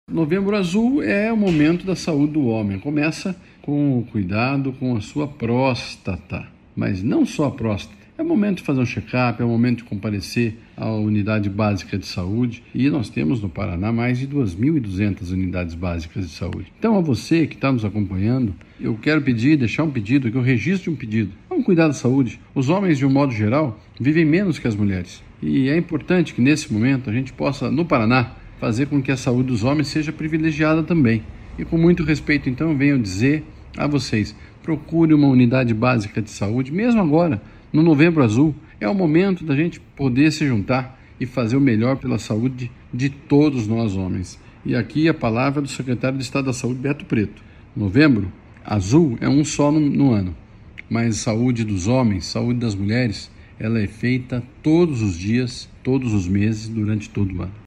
Sonora do secretário da Saúde, Beto Preto, sobre o Novembro Azul